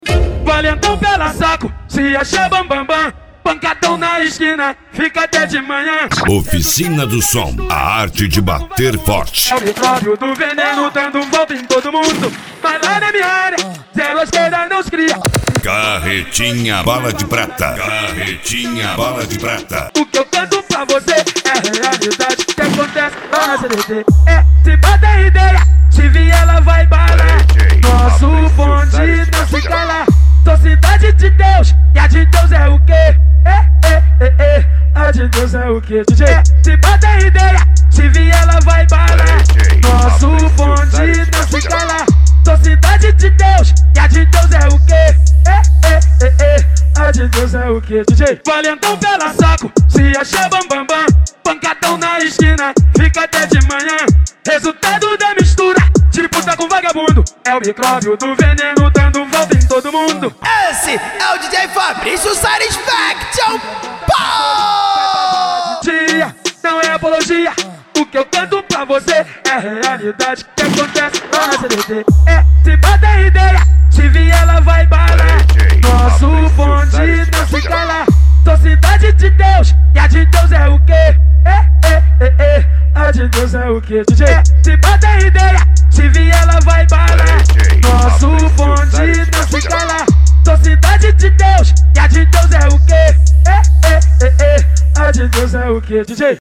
Deep House
Remix
SERTANEJO
Sertanejo Universitario